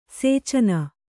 ♪ sēcana